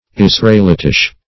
israelitish - definition of israelitish - synonyms, pronunciation, spelling from Free Dictionary
Search Result for " israelitish" : The Collaborative International Dictionary of English v.0.48: Israelitic \Is`ra*el*it"ic\, Israelitish \Is"ra*el*i`tish\, a. Of or pertaining to Israel, or to the Israelites; Jewish; Hebrew.